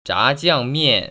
[zhájiàngmiàn] 자지앙미앤